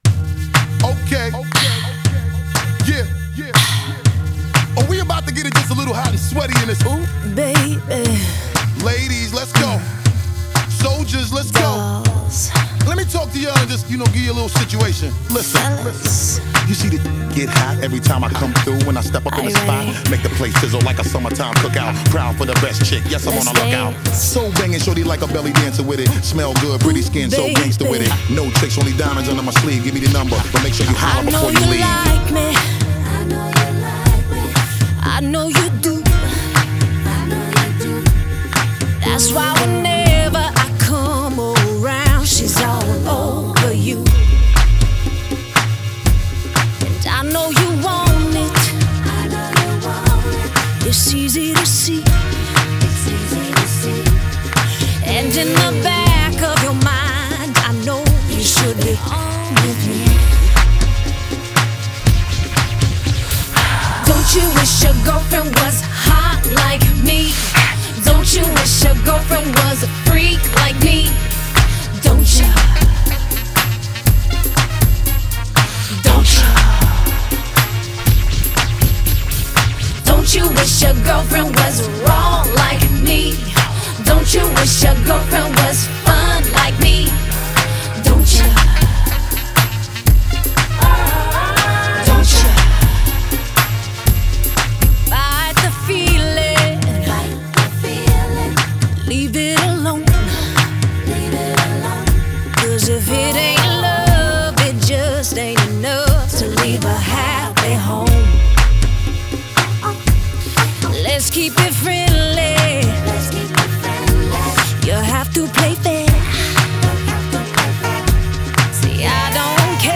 Pop 2000er